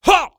ZS普通攻击2.wav
ZS普通攻击2.wav 0:00.00 0:00.39 ZS普通攻击2.wav WAV · 34 KB · 單聲道 (1ch) 下载文件 本站所有音效均采用 CC0 授权 ，可免费用于商业与个人项目，无需署名。
人声采集素材/男3战士型/ZS普通攻击2.wav